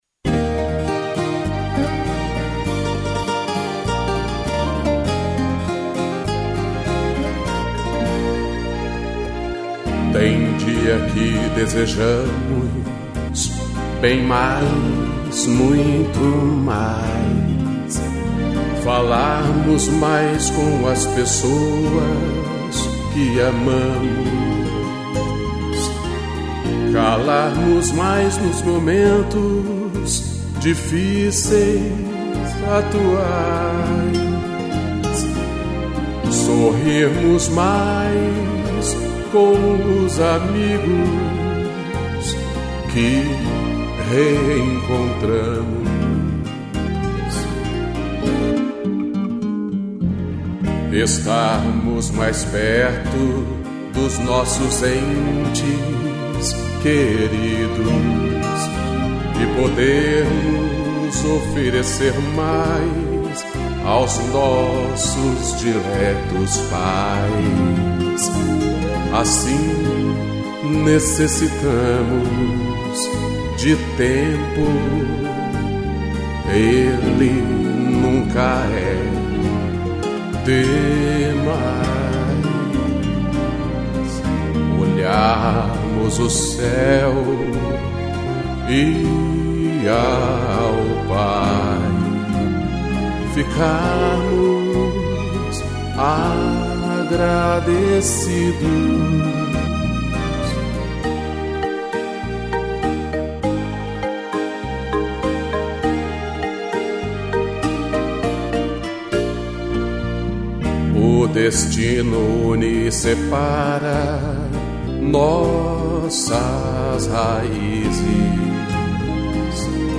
voz e violão
strings
(sem bateria)